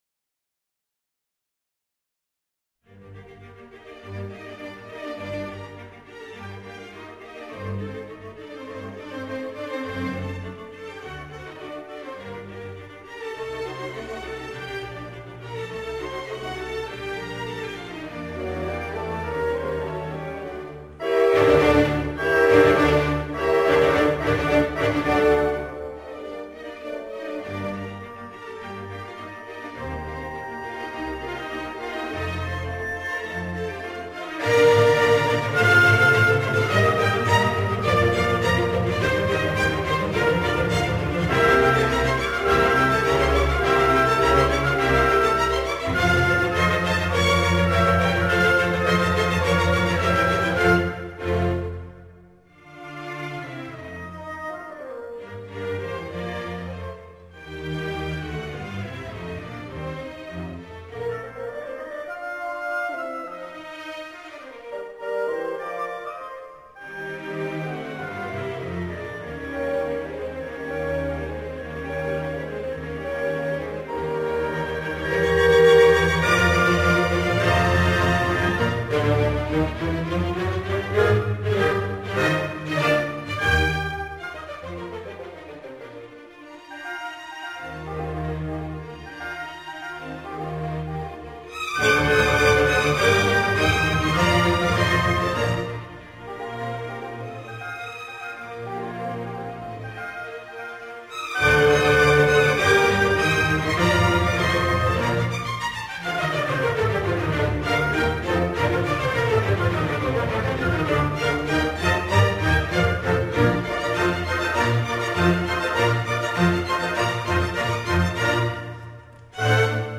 Simfonia núm 40 de Mozart.mp3